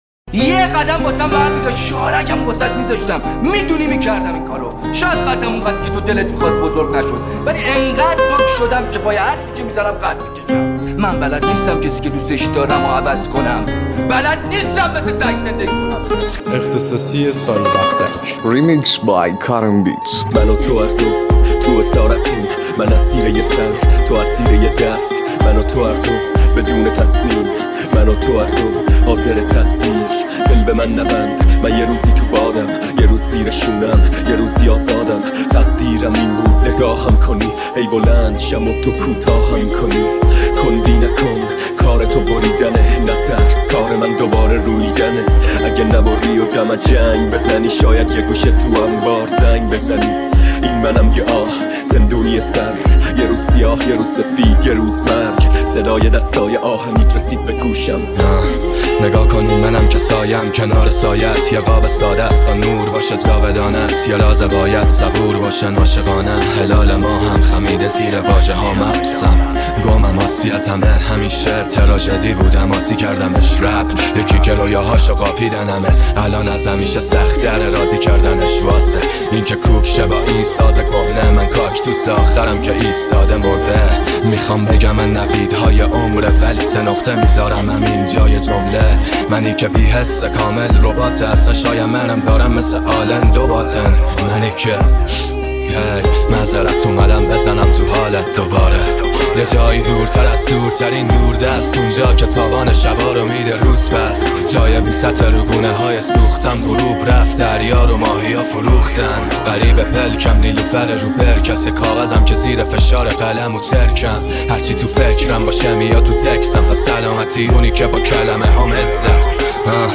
ریمیکس رپی